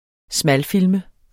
Udtale [ ˈsmal- ]